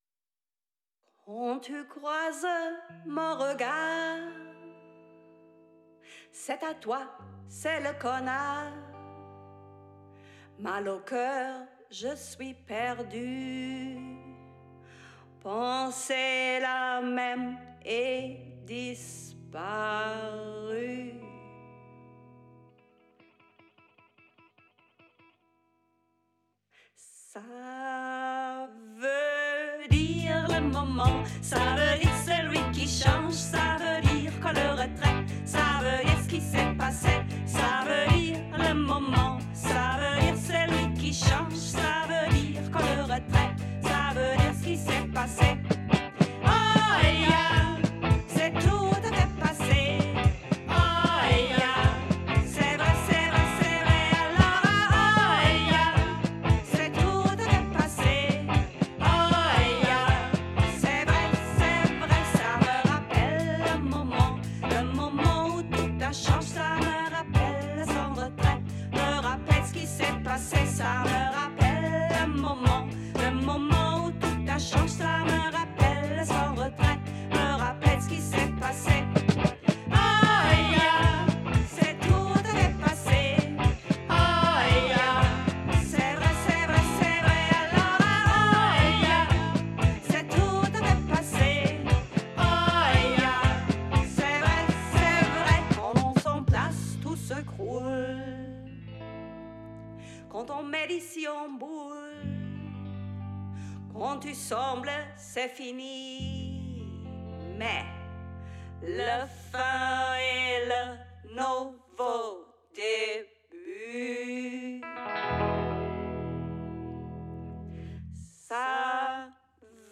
Diese 2 Songs haben wir im Tonstudio eingespielt: